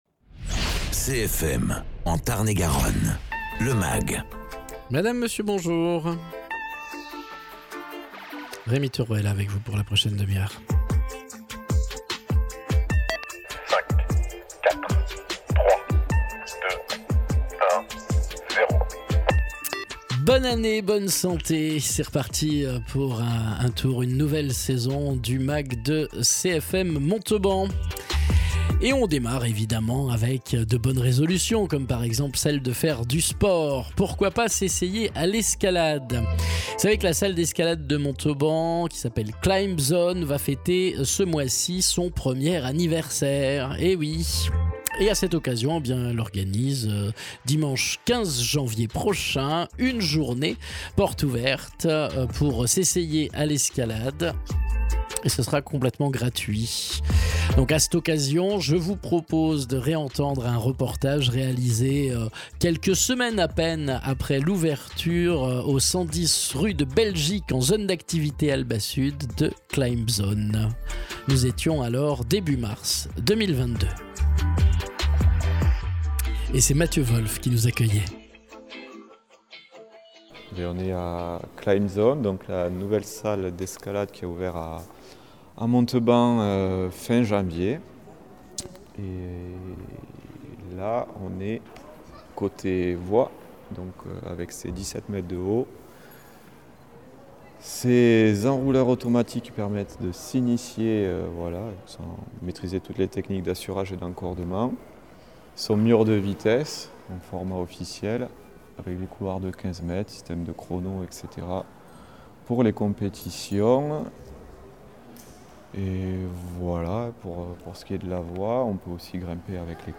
À l’occasion de la journée porte ouverte du 15 janvier prochain, reportage à Climb’zone, la salle d’escalade de Montauban située au 110 rue de Belgique en zone Albasud.